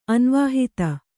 ♪ anvāhita